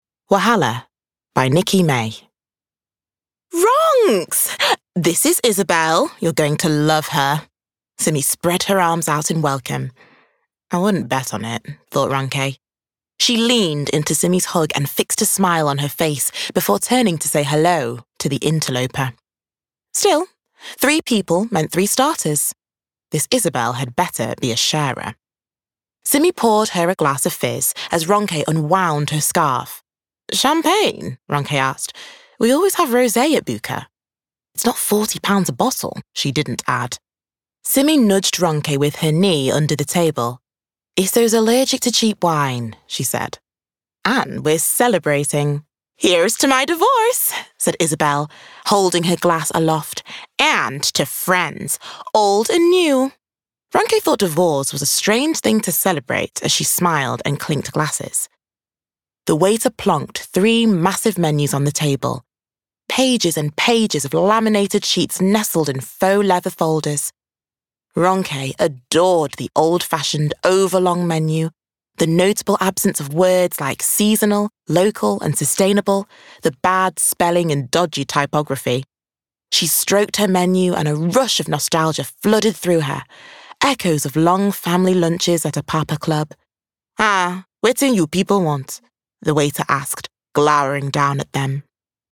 Audio Book Showreel
Female
Manchester